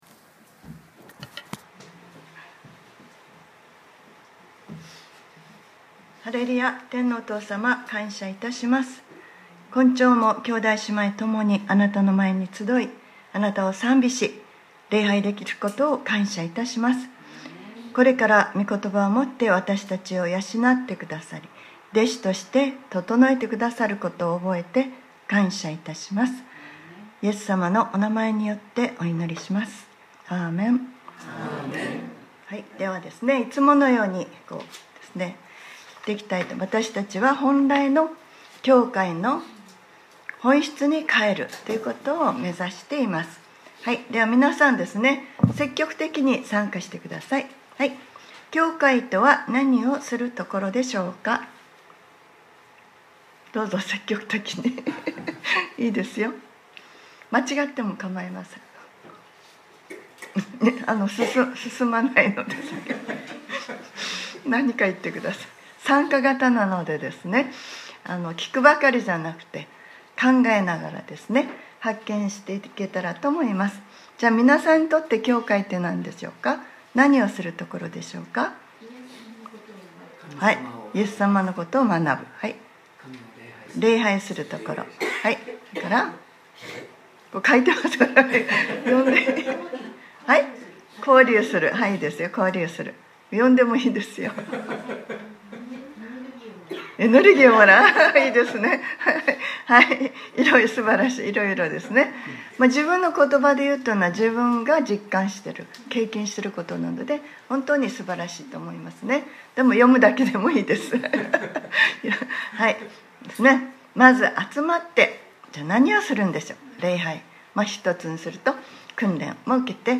2019年11月17日（日）礼拝説教『活かす文化』